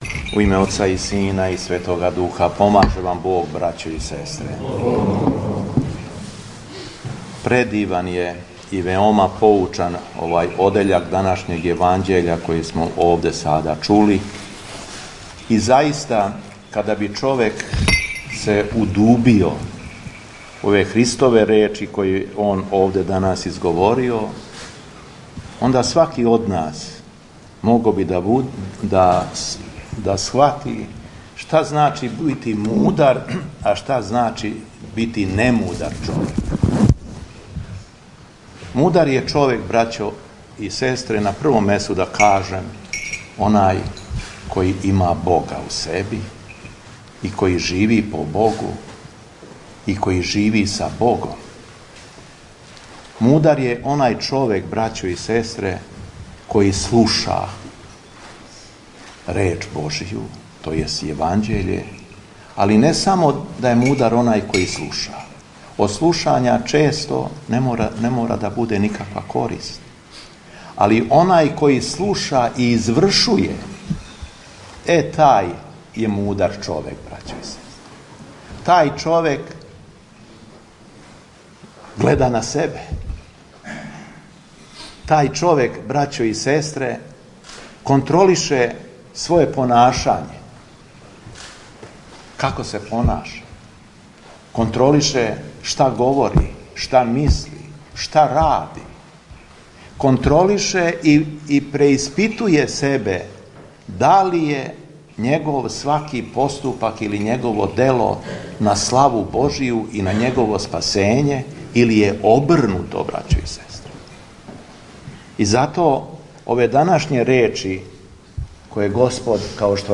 СВЕТА АРХИЈЕРЕЈСКА ЛИТУРГИЈА У СЕЛУ ВЛАШКА - Епархија Шумадијска
Беседа Епископа шумадијског Г. Јована